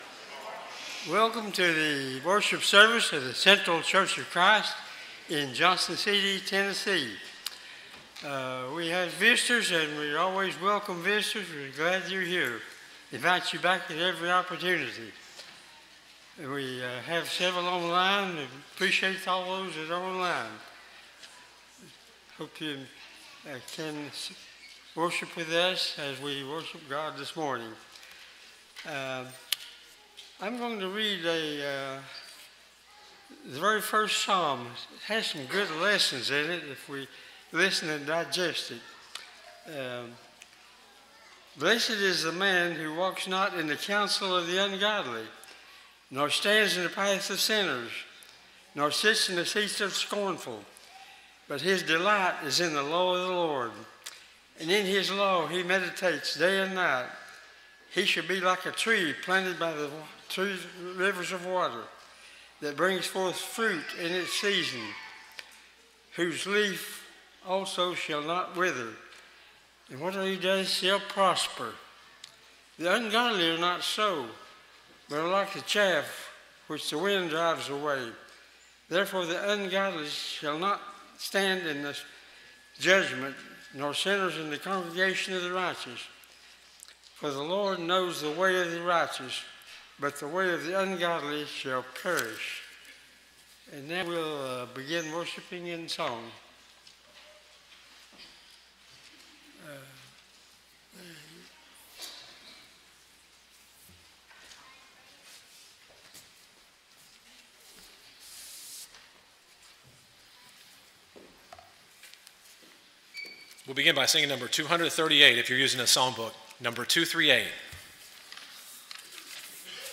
Titus 2:11, English Standard Version Series: Sunday AM Service